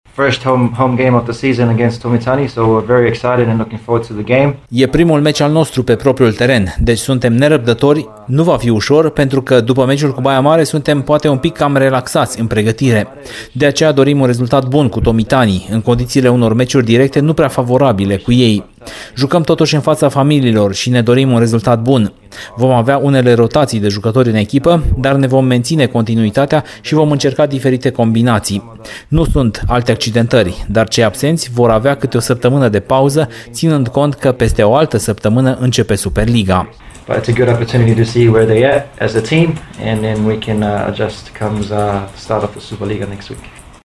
Intențiile staff-ului tehnic au fost făcute publice cu ocazia conferinței de presă dinaintea jocului de sâmbătă, ora 11, cu Tomitanii Constanța, de pe stadionul ”Dan Păltinișanu”.